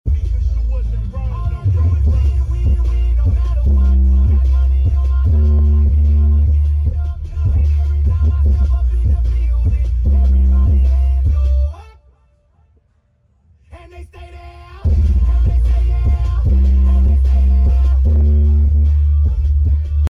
Background music is used for demo purpose only.